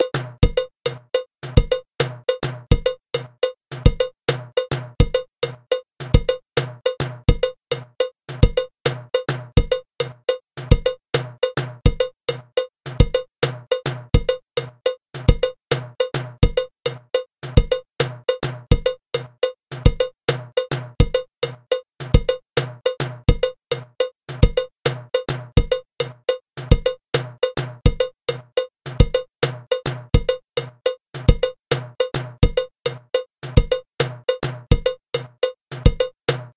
Le Merensongo se joue à tempo modéré.
Merensongo (batterie)
Rythme de batterie
Le jeu de batterie et de congas est accompagné d'un rythme de clave rumba en 3/2.
merensongo_batterie3.mp3